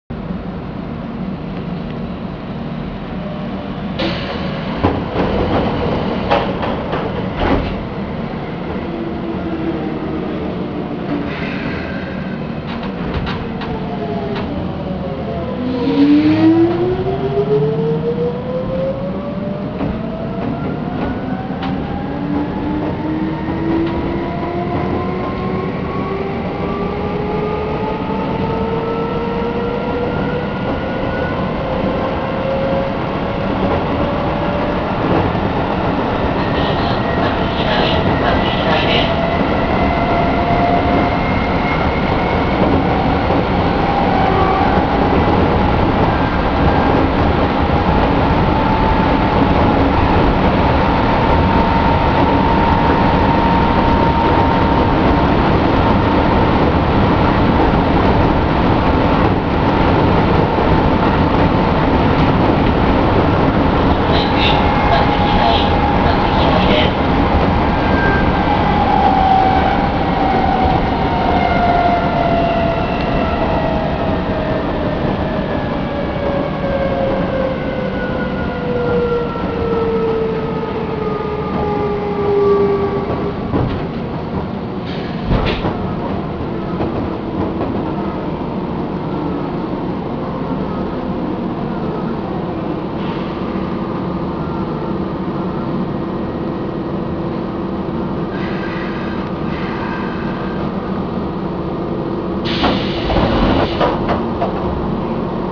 ・3400形走行音
【北総線】大町〜松飛台（1分45秒：574KB）
旧AE形の音そのままになります。基本的には3600形もほぼ同じ音です。起動時にかなり爆音になるのが特徴。この点については3600形よりも大分激しいように感じます。